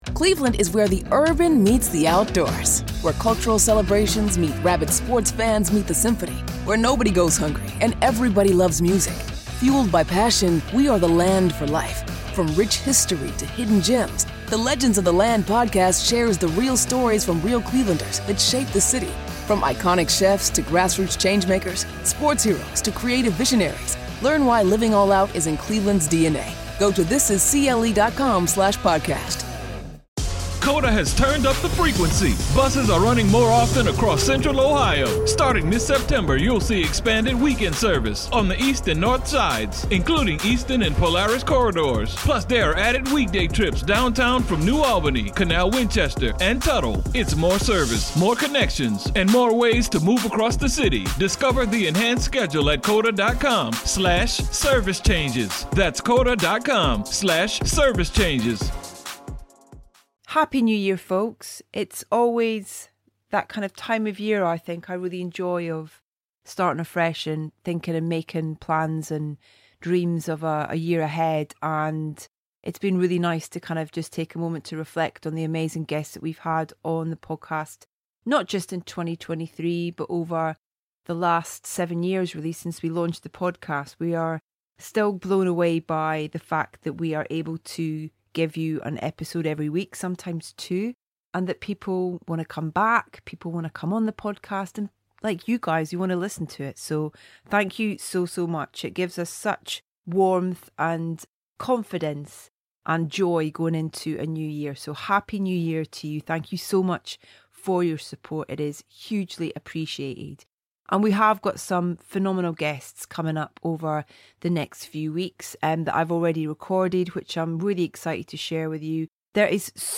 Our first guest of 2024 is the wonderful Sofia Coppola, who joined us for a Soundtracking Everyman Film Club in front of a live audience to discuss her latest film, Priscilla.